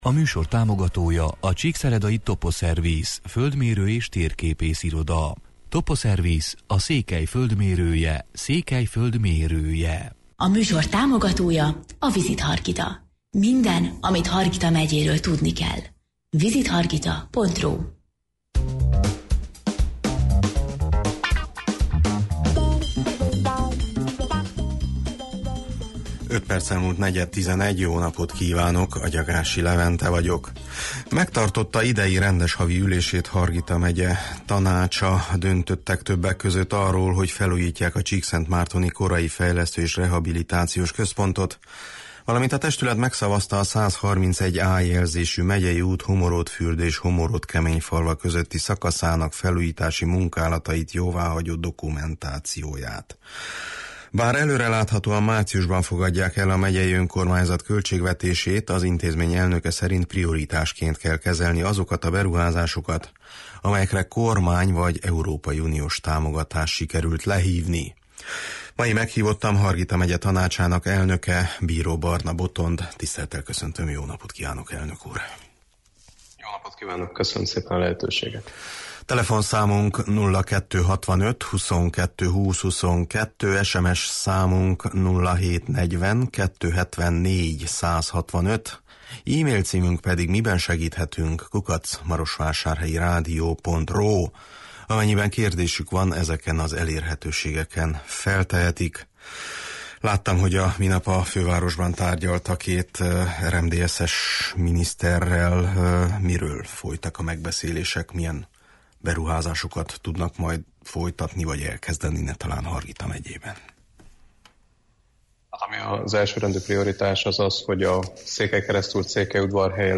Mai meghívottam Hargita Megye Tanácsának elnöke, Bíró Barna Botond, aki arról is beszélt, hogy a közeljövőben átszervezik a megyei önkormányzat apparátusát: